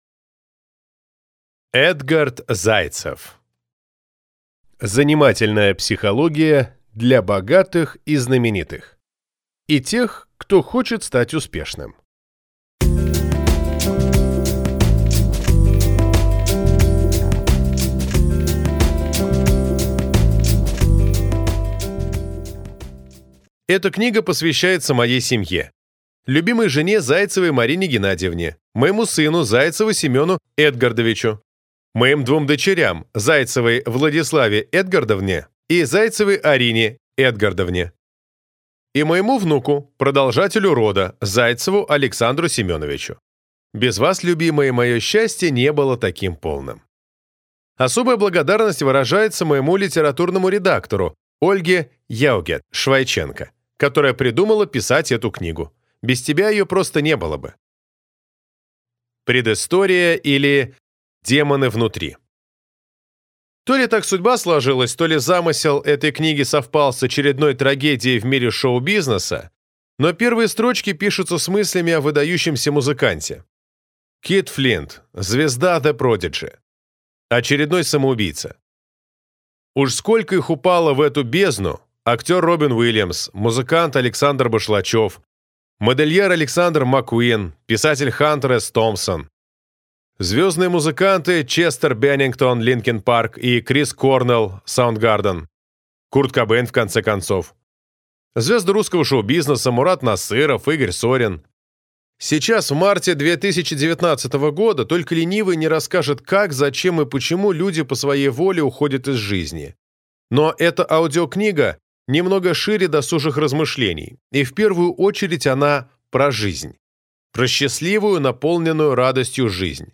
Аудиокнига Занимательная психология для богатых и знаменитых… и тех, кто хочет стать успешным | Библиотека аудиокниг